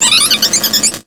Cri de Galvaran dans Pokémon X et Y.